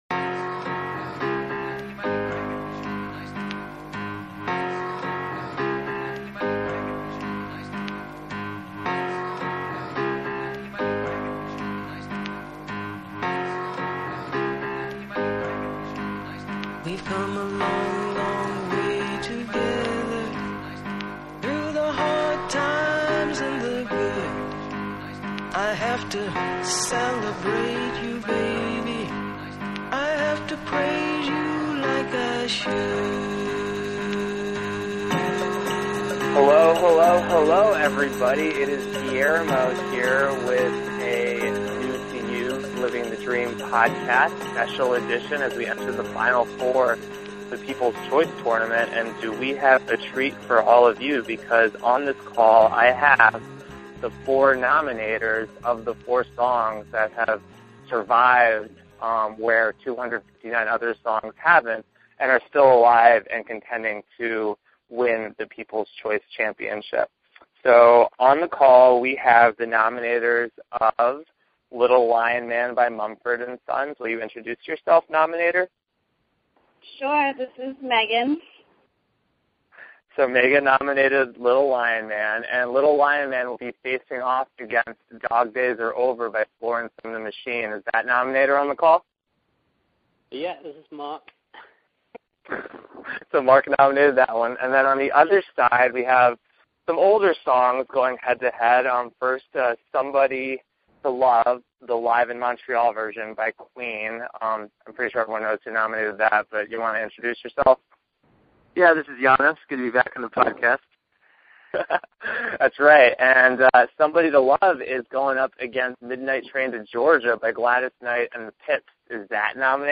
It’s a fun, get-you-in-the-mood for the Final Four kind of chat.